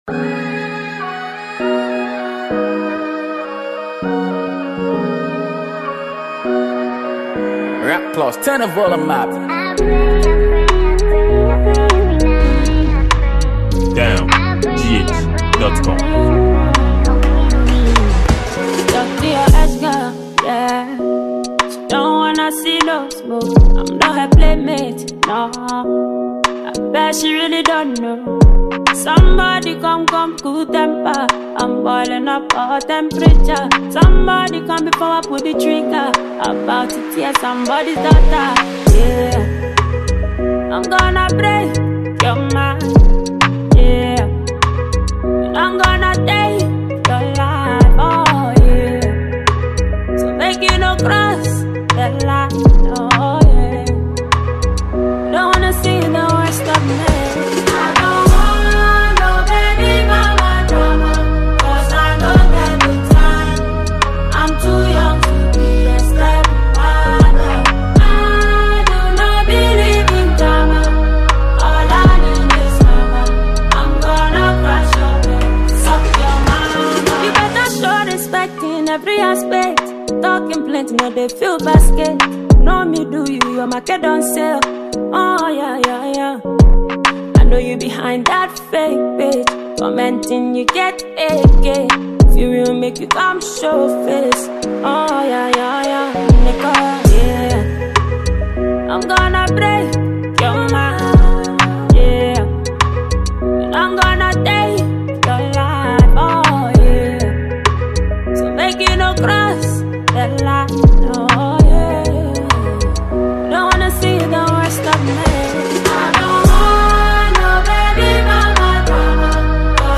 Genre: Afrobeat